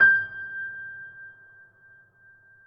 piano-sounds-dev
Steinway_Grand